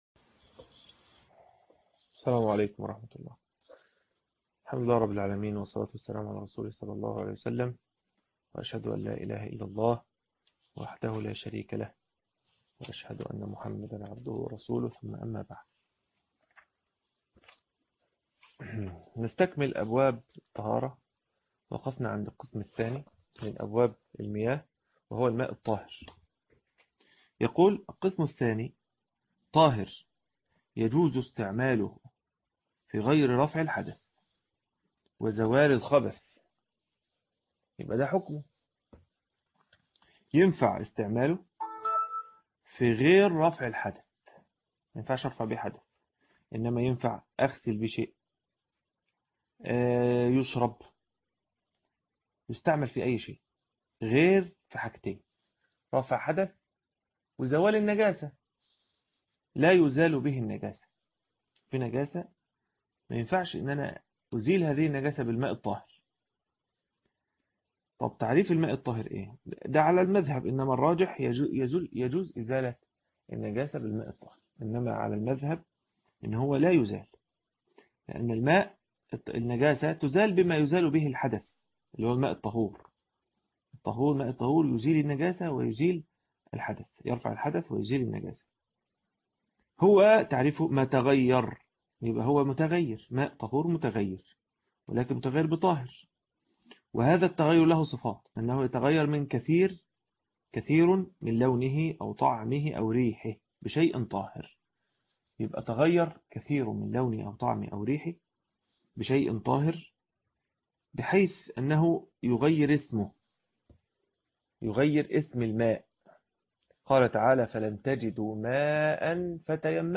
شرح منار السبيل (2)معهد ابن تيمية الفرقة الأولي - الشيخ أبو إسحاق الحويني